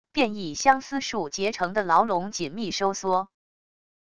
变异相思树结成的牢笼紧密收缩wav音频